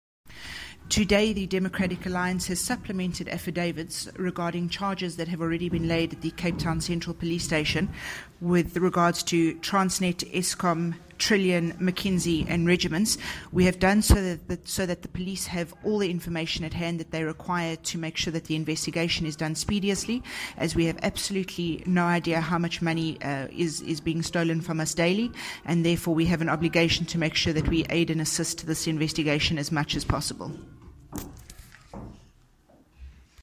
English soundbite by Ms Mazzone and an
Natasha-Mazzone-English.mp3